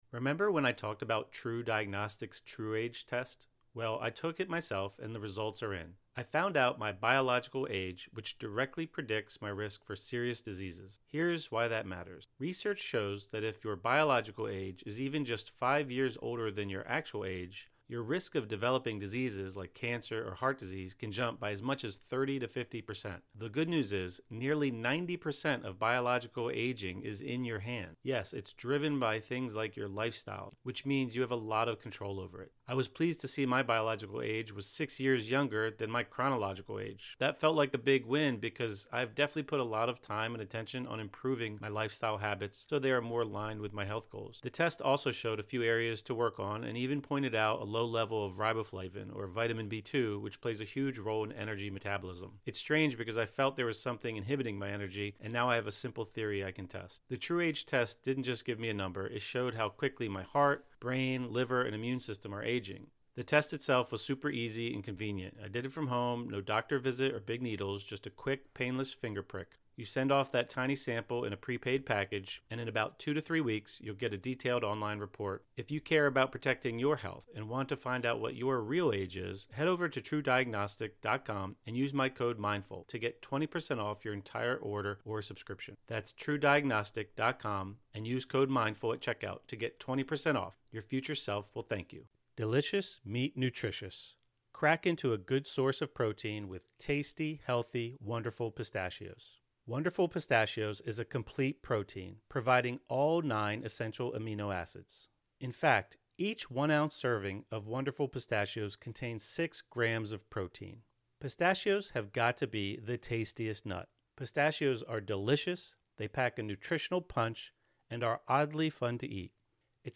Deep Sleep Hypnosis | Evolve and Expand into New Beginnings (; 15 Jun 2025) | Padverb